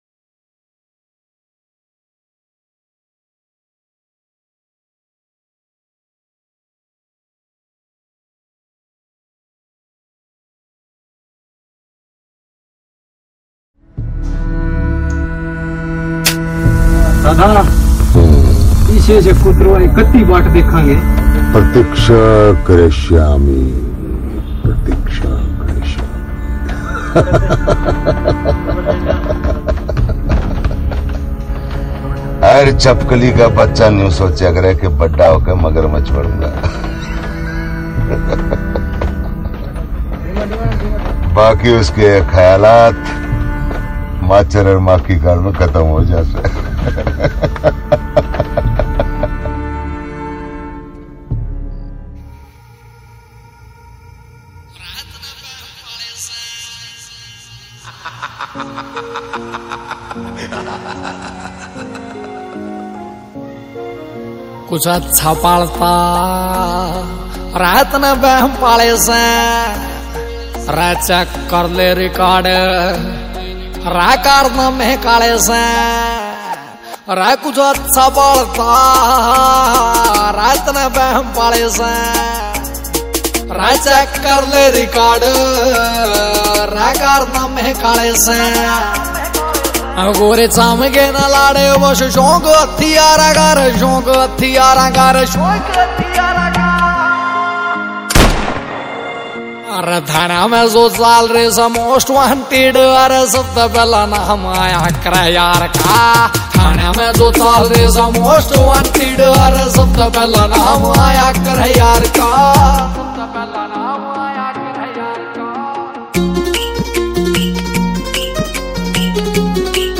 DJ Remix Songs
» Haryanvi Songs